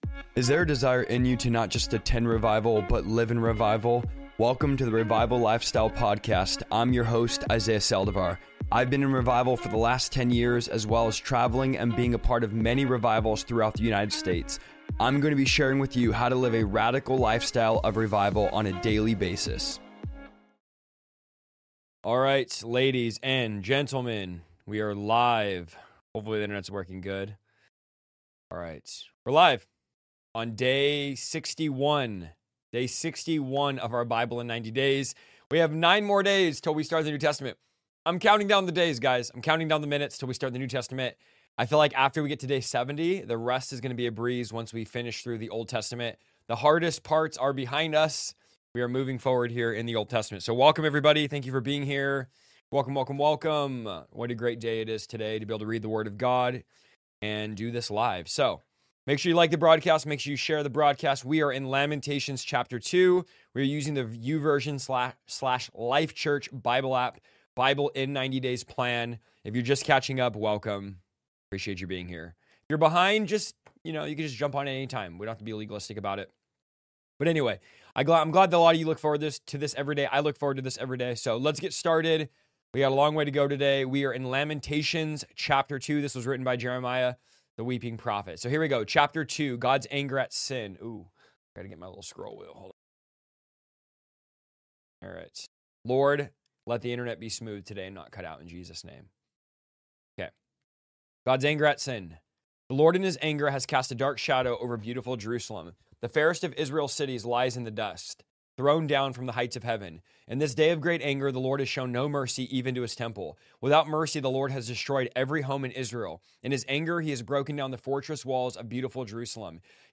Every day, we'll dive into Scripture together in a relaxed, interactive live session where you can ask questions, share thoughts, and explore the Bible in a way that feels personal and genuine. Here’s what you can look forward to: Daily Bible Reading: I'll be reading through the Bible live, sharing my insights and reflections as we move chapter by chapter.